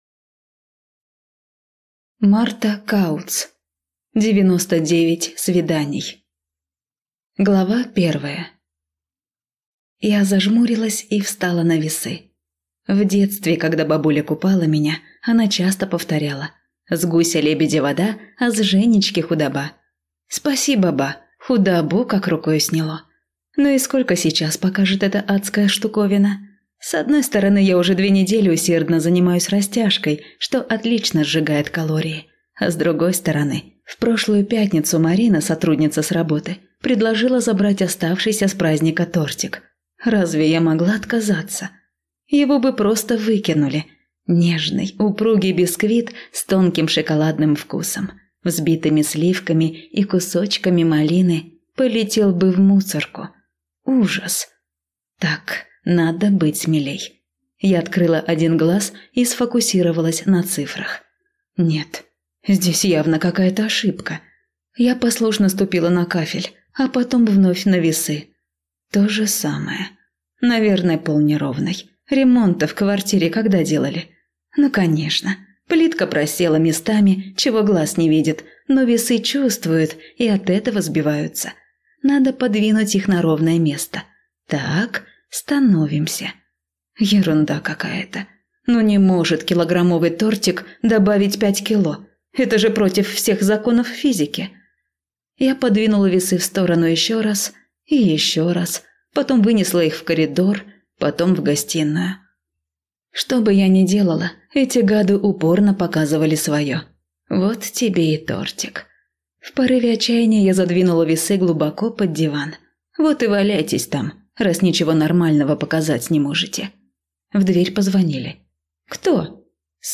Аудиокнига 99 свиданий | Библиотека аудиокниг